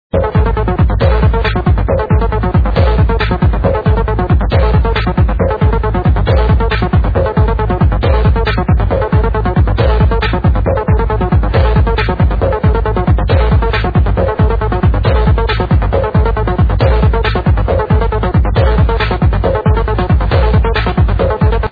Same liveset as in my first thread.